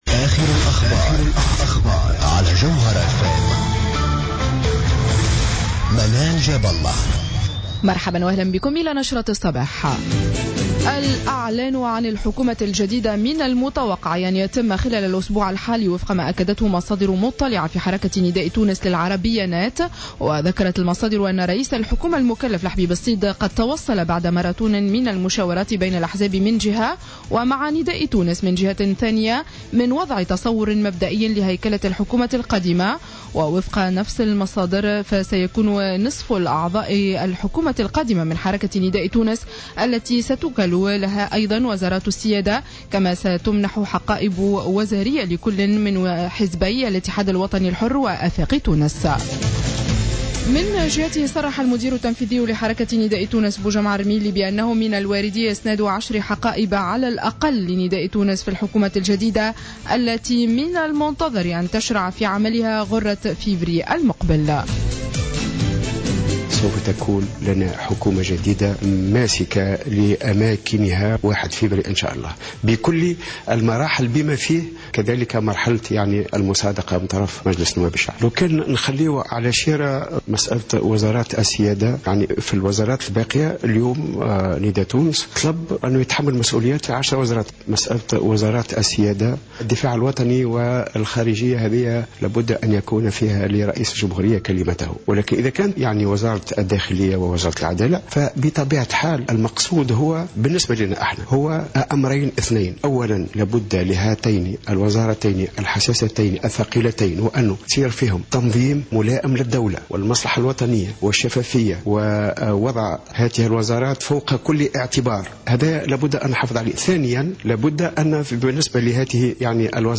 نشرة اخبار السابعة صباحا ليوم الإثنين 19-01-15